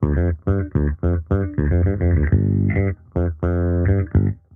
Index of /musicradar/sampled-funk-soul-samples/105bpm/Bass
SSF_JBassProc1_105G.wav